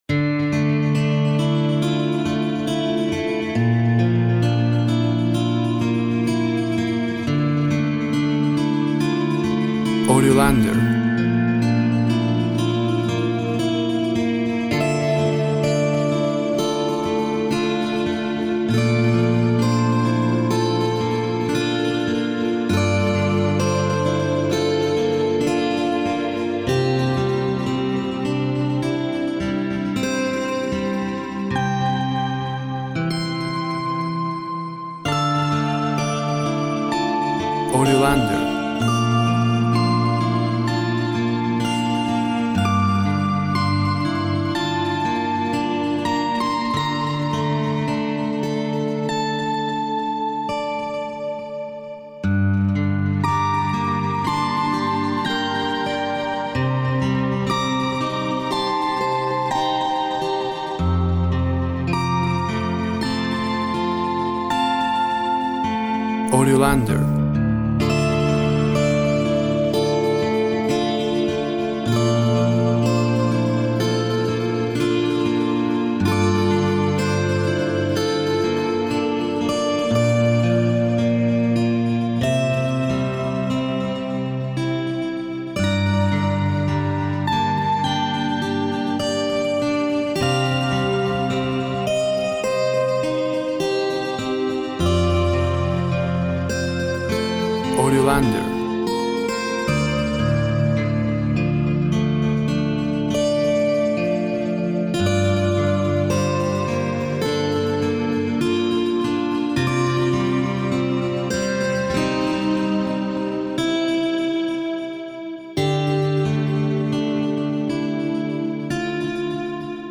Guitars and reeds sing a song of contemplation.
Tempo (BPM) 52